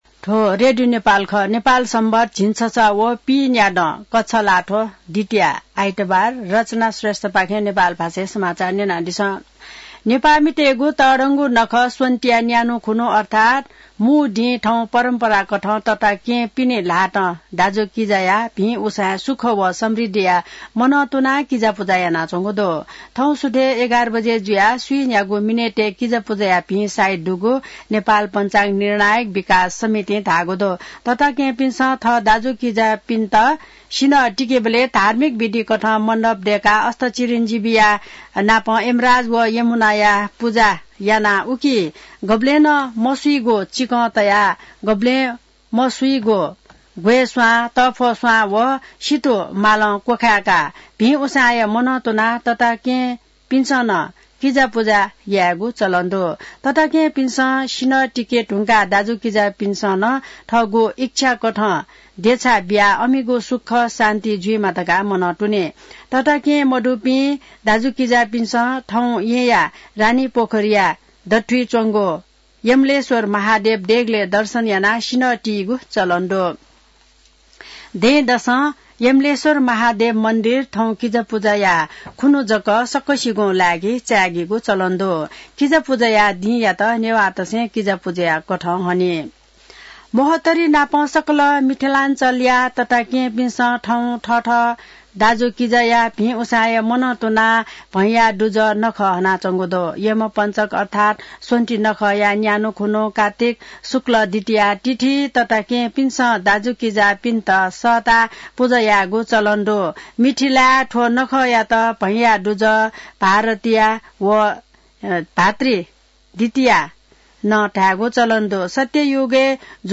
नेपाल भाषामा समाचार : १९ कार्तिक , २०८१